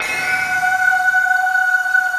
SI1 BAMBO08L.wav